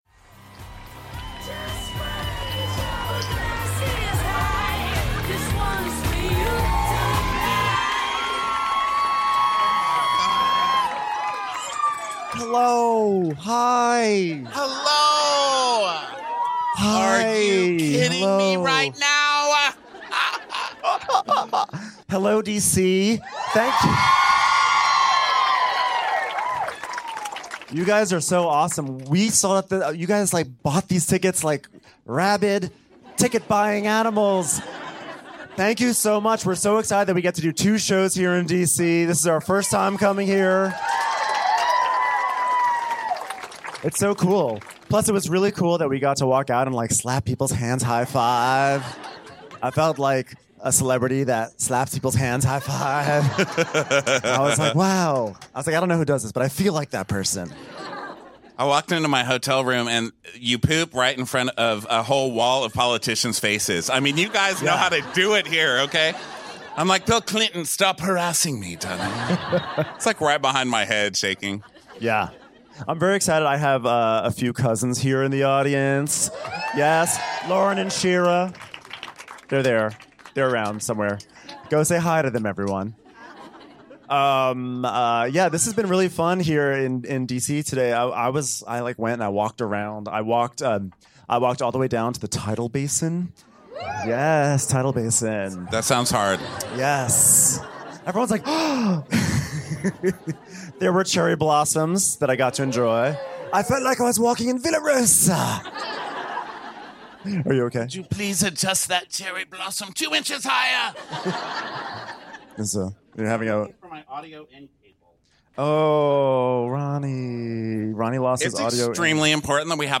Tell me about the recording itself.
#674 Crappens Live DC Pump Rules: Pillow Talk